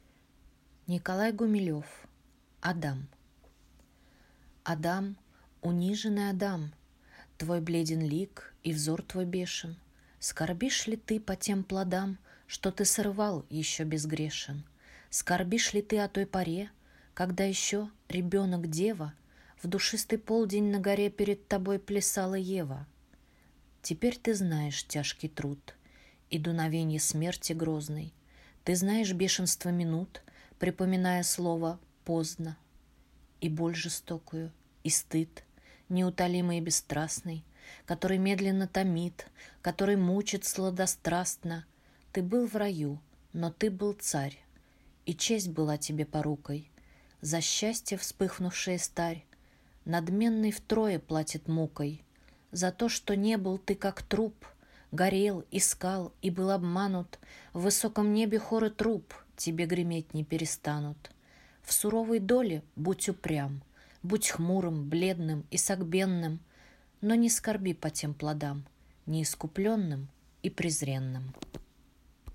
Название произведения: «Адам», автор: Гумилев Николай Степанович , жанр: Поэзия